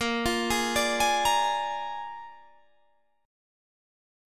Listen to A#7 strummed